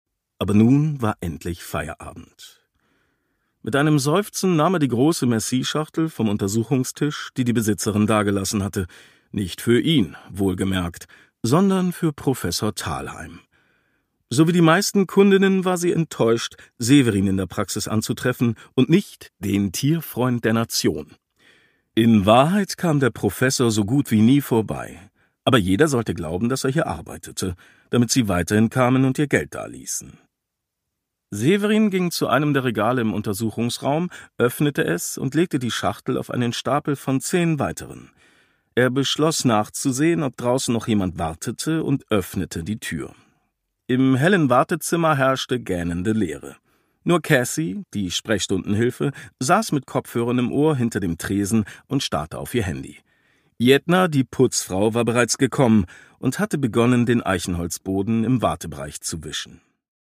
René Anour: Der Doktor und der liebe Mord - Ein Tierarzt-Krimi (Ungekürzte Lesung)
Produkttyp: Hörbuch-Download